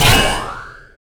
SI2 SCHUNK.wav